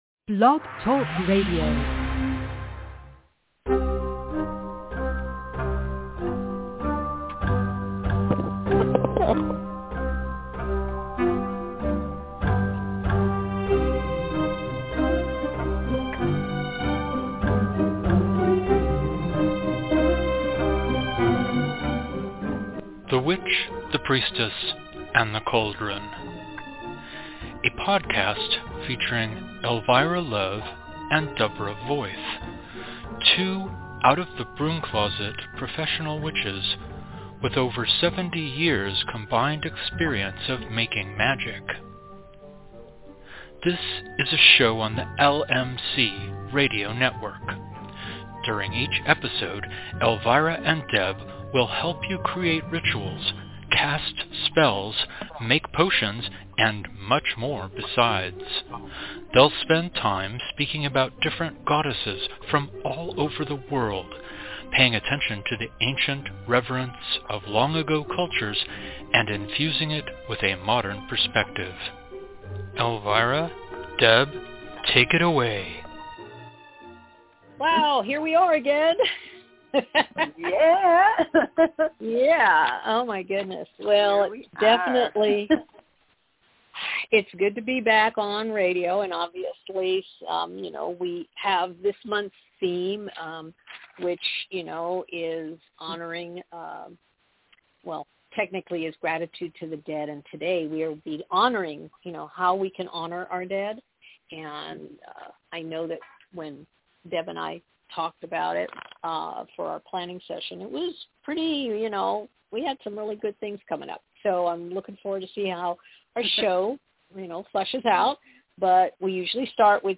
A new episode of The Witch, The Priestess, and The Cauldron air LIVE every Thursday at 4pm PT / 7pm ET!